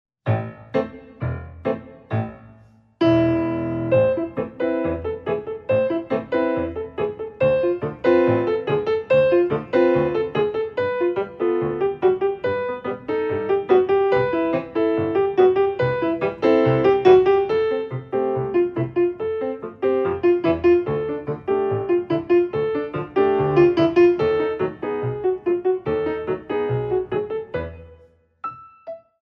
Ballet class music for Intermedite Level
Beautifully recorded on a Steinway and Sons Grand Piano